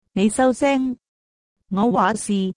nei sau seng! ngo wa si!